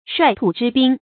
率土之滨 shuài tǔ zhī bīn 成语解释 沿着王土的边涯。